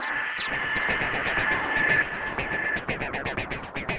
Code Red (Noise) 120BPM.wav